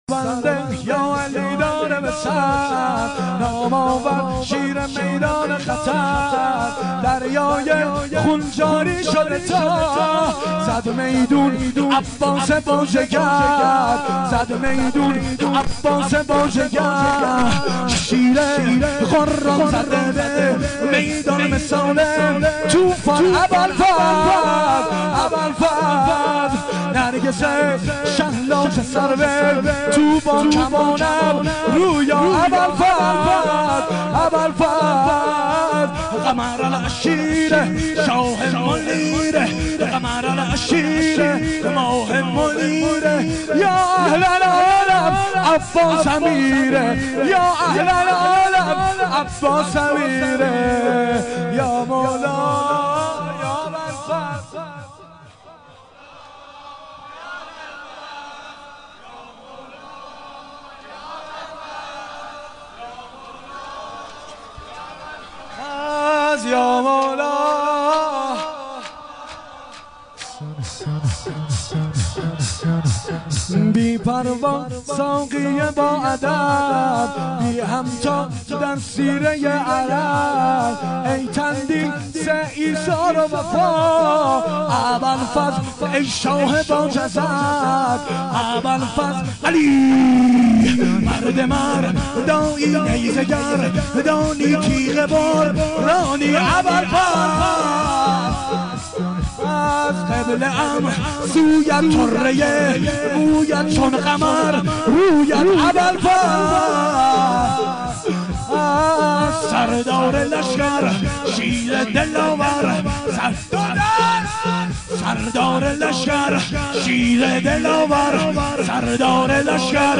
شور
شب دوم محرم ۱۴۴۱